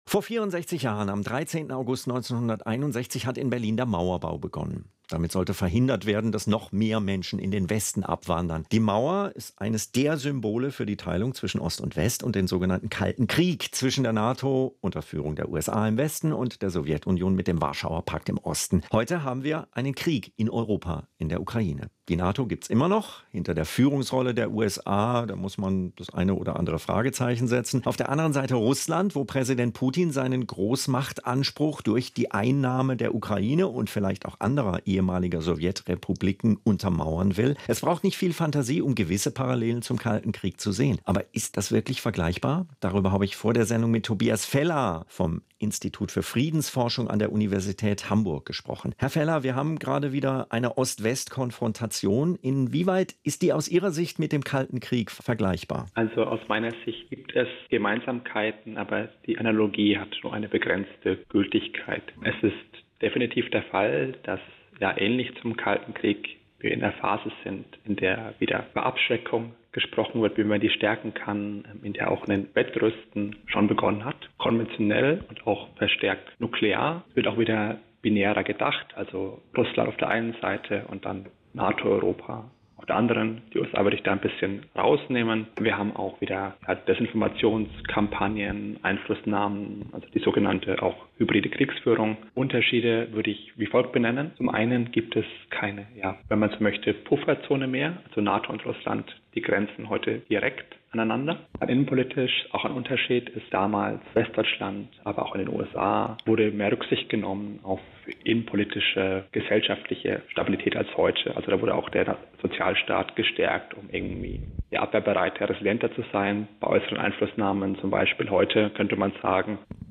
Interview mit dem SWR-Hörfunk (Mit freundlicher Genehmigung von SWR Kultur).